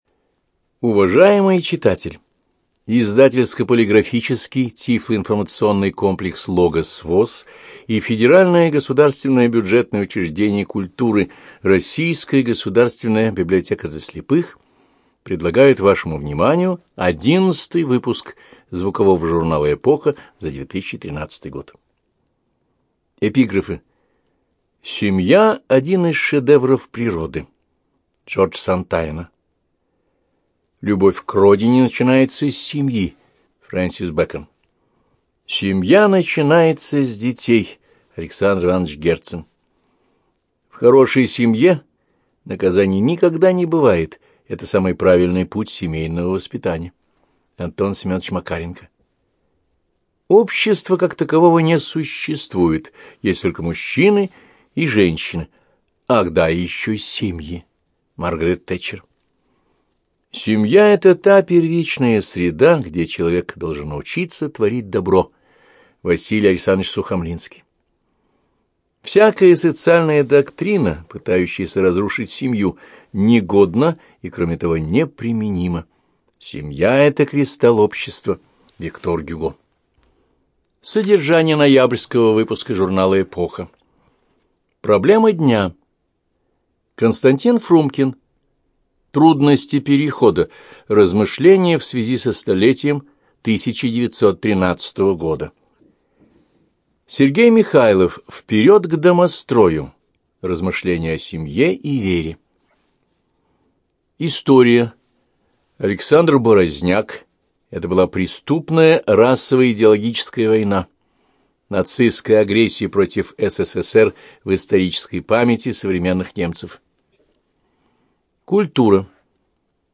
ЖанрПублицистика
Студия звукозаписиЛогосвос